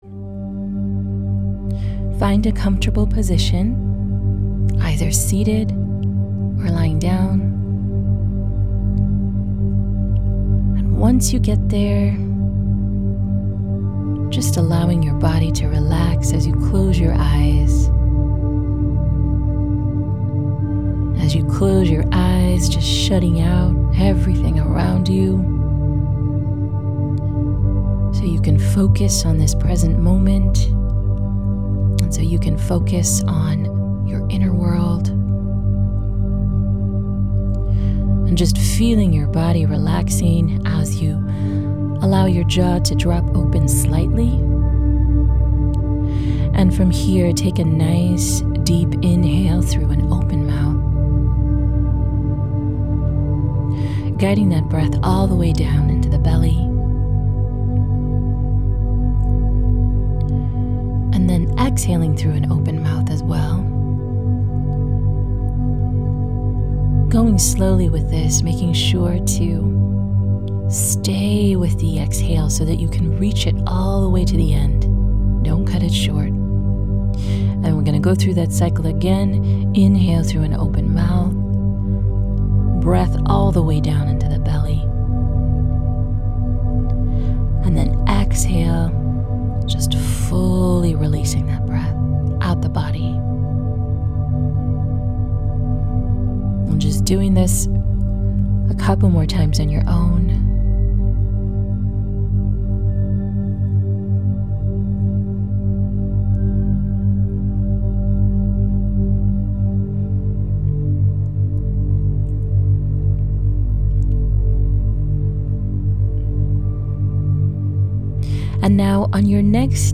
Deep Breathing Meditation
For a deeper experience, use headphones for this exercise.